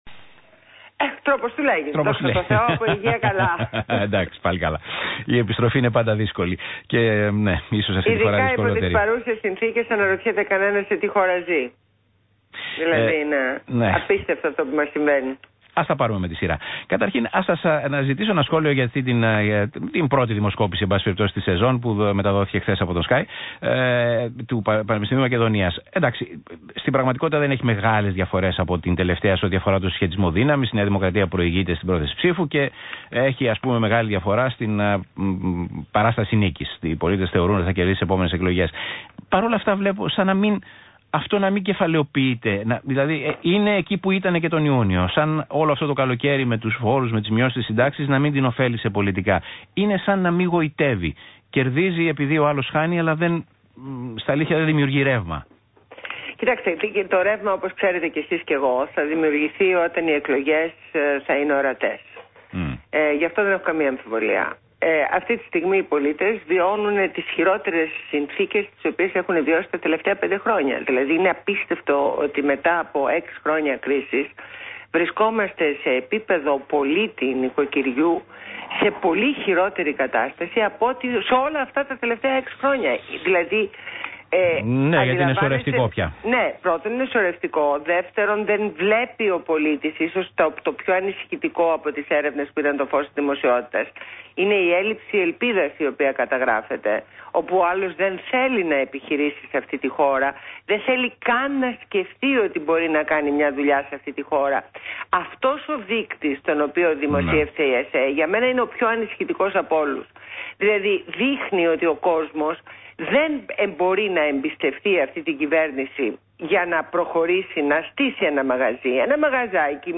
Συνέντευξη στο ραδιοφωνο του ΣΚΑΙ στο δημοσιογράφο Π. Τσίμα.